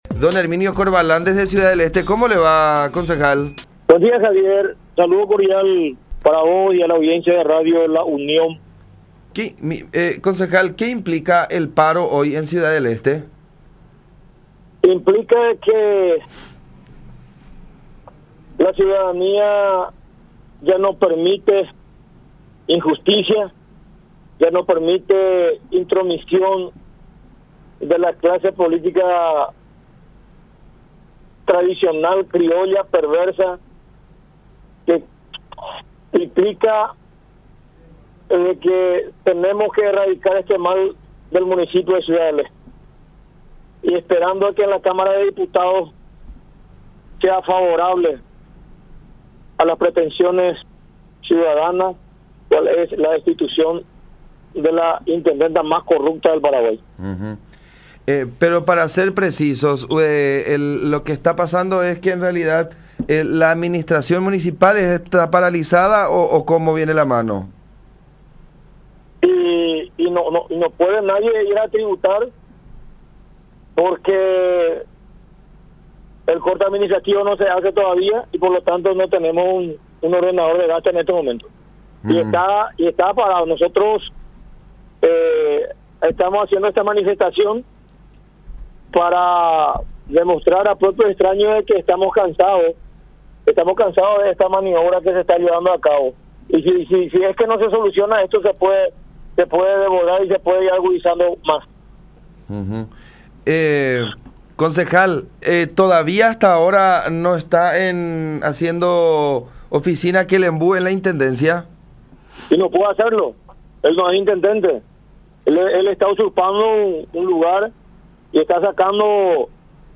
“La ciudadanía ya no permite injusticias ni la política tradicional, criolla y perversa (…) Tenemos que erradicar este mal del municipio de Ciudad del Este, a la intendenta más corrupta del Paraguay”, expresó Herminio Corvalán, concejal esteño, en diálogo con La Unión, ratificando su posición de que “Kelembu” Miranda no es su sucesor.